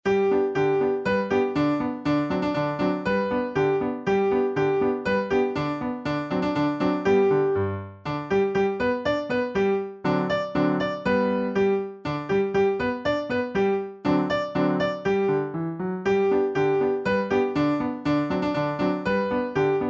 Traditional French Song Lyrics and Sound Clip